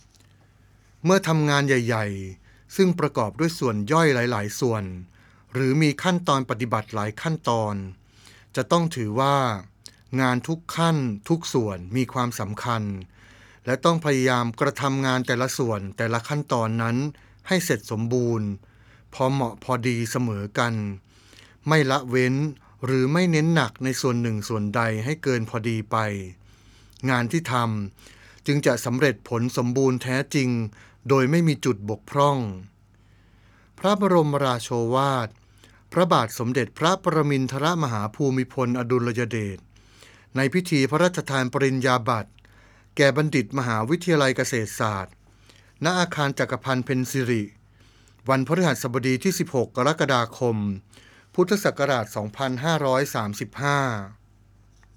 พระบรมราโชวาท
ในพิธีประสาทปริญญาบัตรและอนุปริญญาบัตร ของมหาวิทยาลัยเกษตรศาสตร์